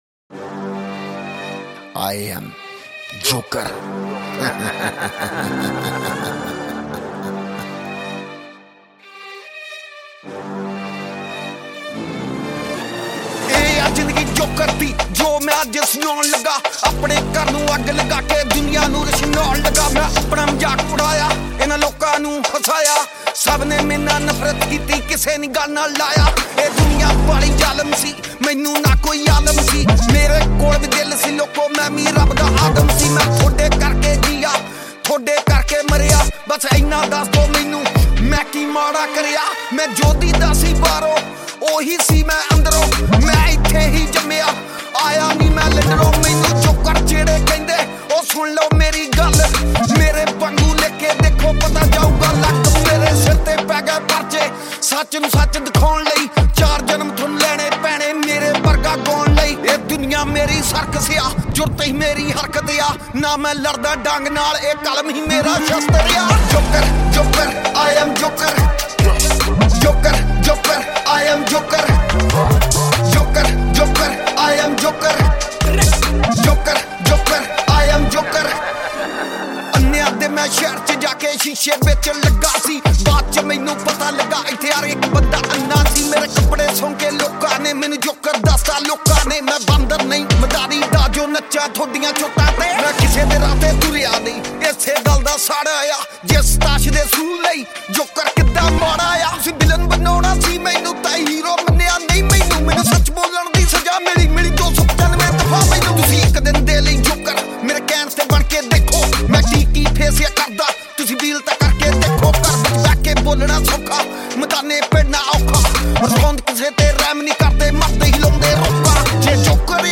Releted Files Of Punjabi Music